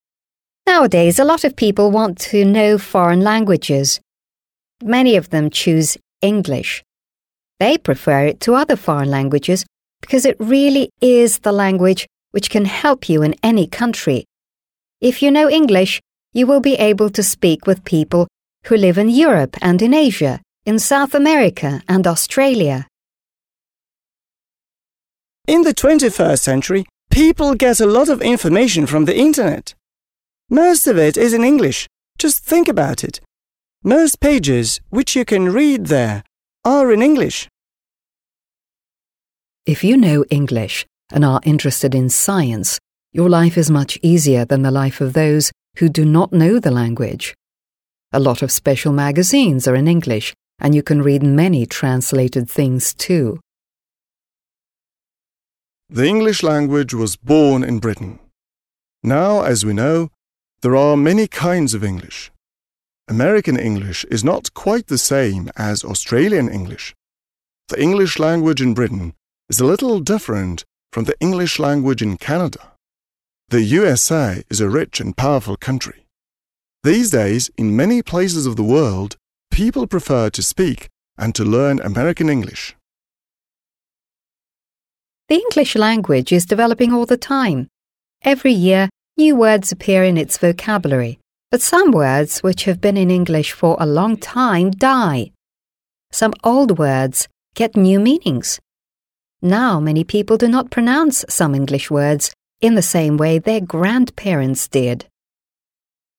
Listen to the five speakers talking about English.